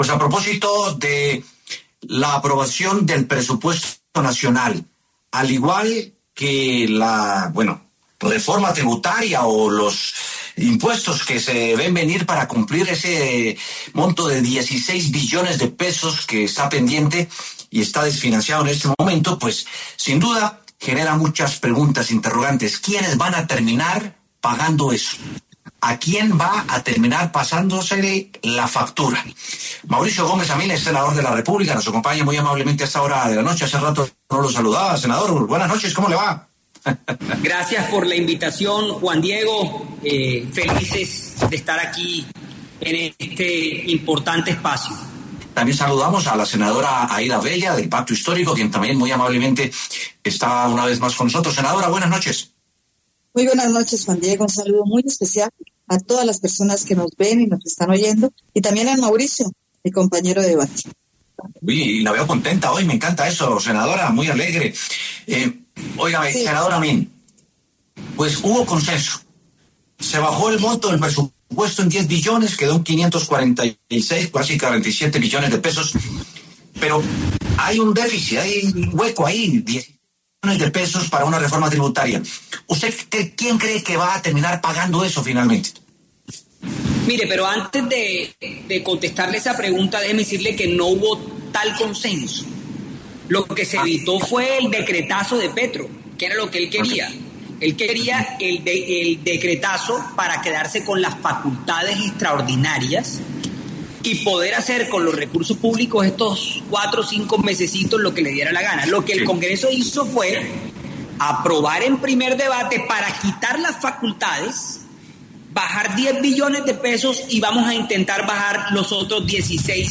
Los congresistas Mauricio Gómez y Aída Avella, debatieron el Presupuesto General y la reforma tributaria.
Este jueves, 25 de septiembre, los congresistas Mauricio Gómez del Partido Liberal y Aída Avella de la Unión Patriótica estuvieron en W Sin Carreta y debatieron la reforma tributaria planteada por el Gobierno Nacional.